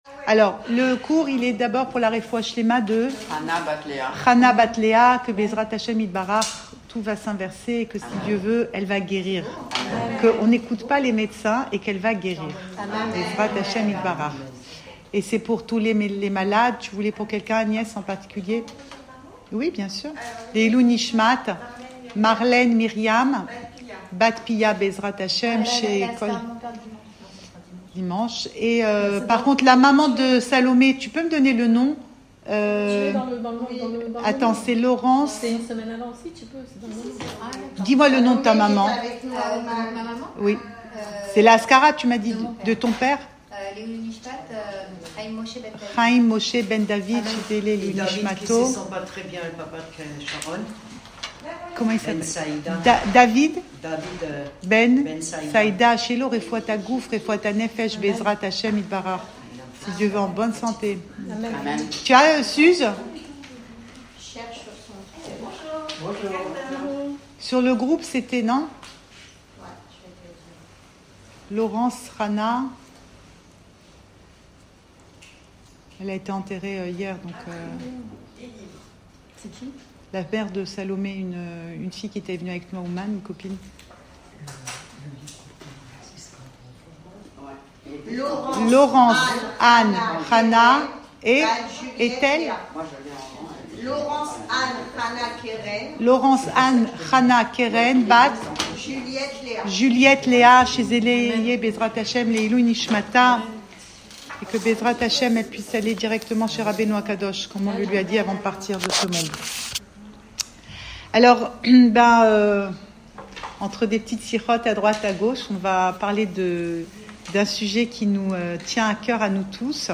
Cours audio Emouna Le coin des femmes Le fil de l'info Pensée Breslev - 19 mars 2025 19 mars 2025 Un temps pour tout. Enregistré à Tel Aviv